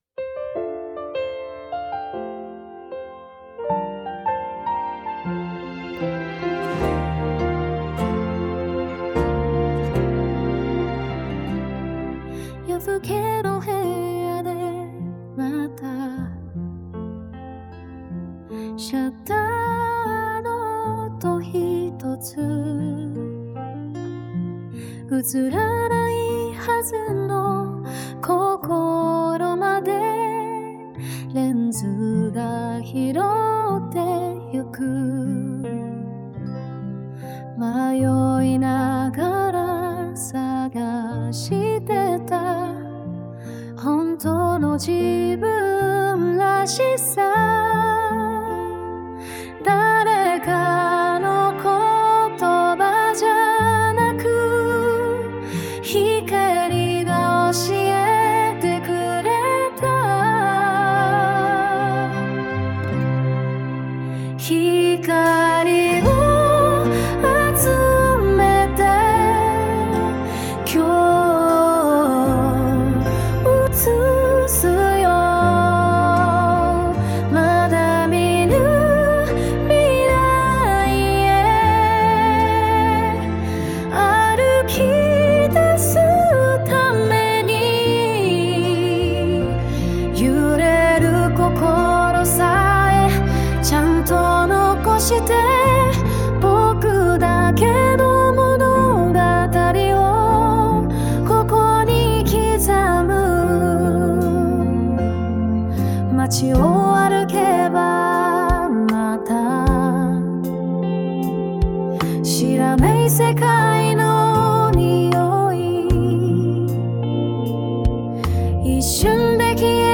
ChatGPTと音楽を生成するAIを使って音楽作り始めましたｗ